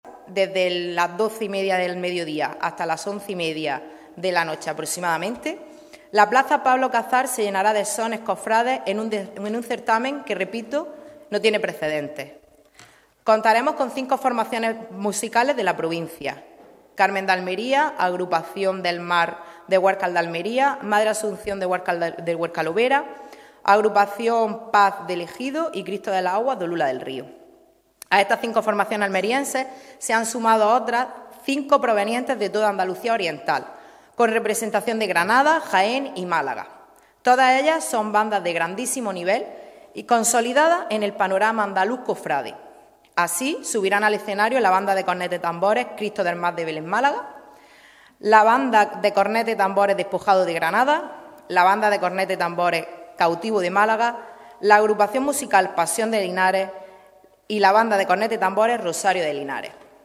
LORENA-NIETO-CONCEJALA-EMPLEO-COMERCIO-JUVENTUD-Y-EMPRENDIMIENTO.mp3